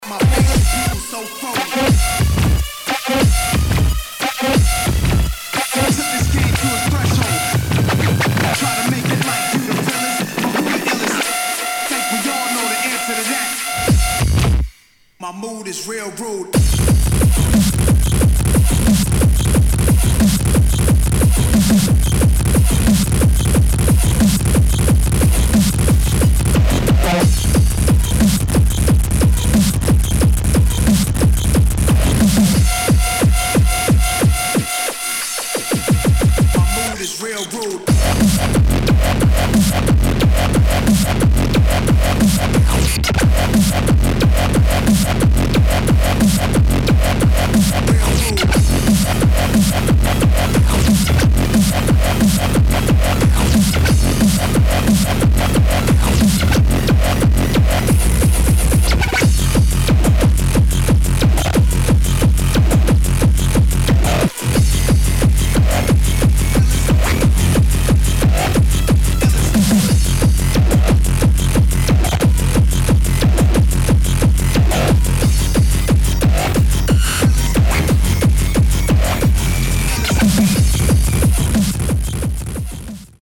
[ HARDCORE / GABBA / INDUSTRIAL ]